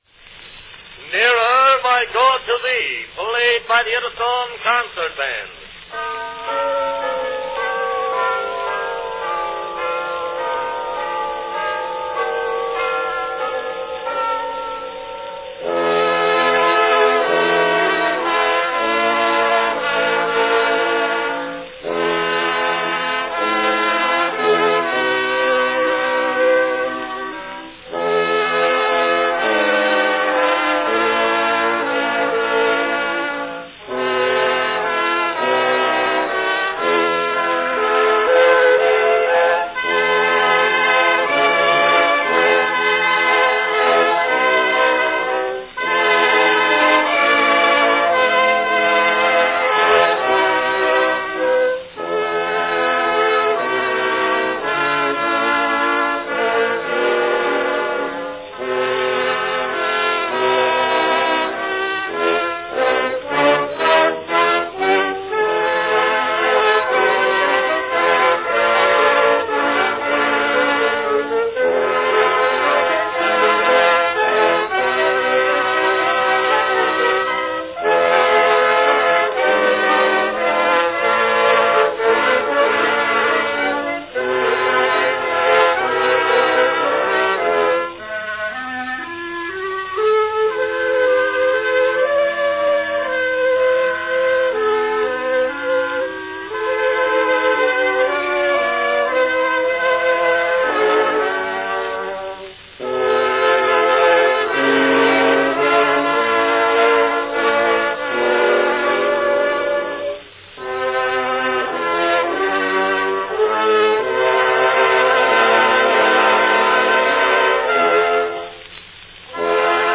Category Band
Performed by Edison Concert Band
Announcement "Nearer My God to Thee, played by the Edison Concert Band."
A very popular song, and a cylinder recording found in many homes of that time, it is not surprising and was certainly appropriate that the band chose this hymn to play.